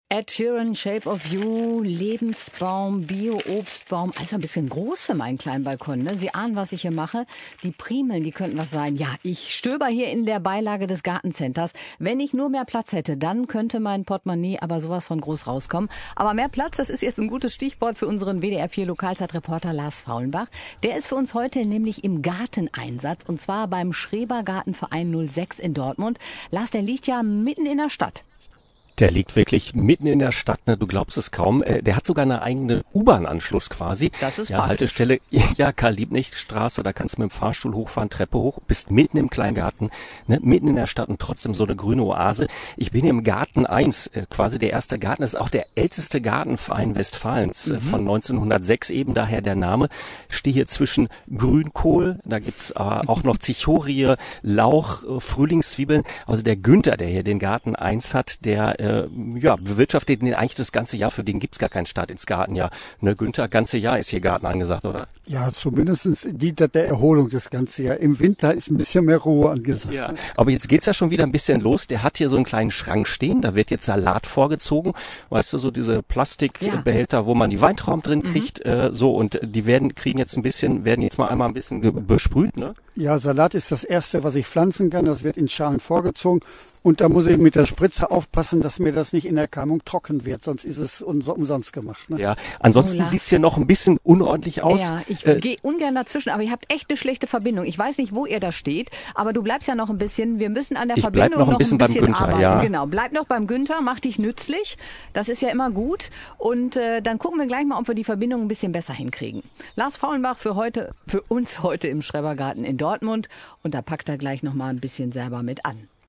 Leider gab es Empfangsprobleme, sodass die 1. Schalte früher als geplant abgebrochen werden musste.